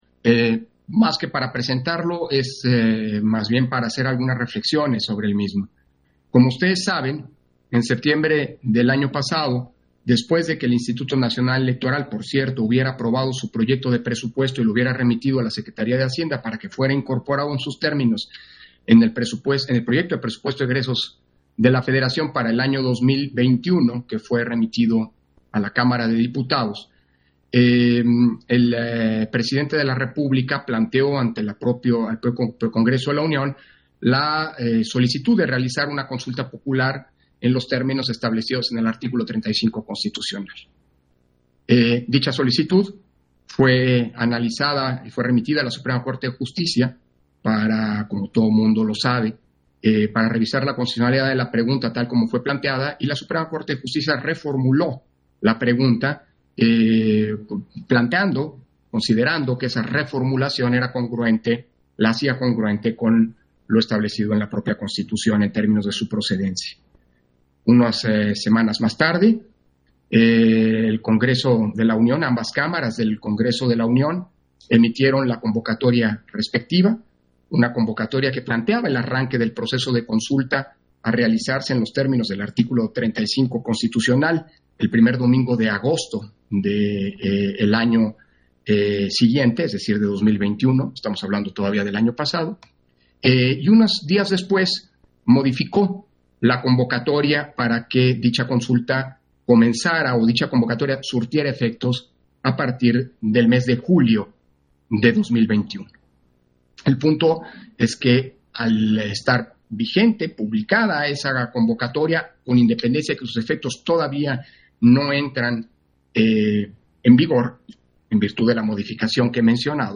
Intervención de Lorenzo Córdova en Sesión Extraodinaria, en el punto en que se aprueba el Plan Integral y Calendario de la Consulta popular 2021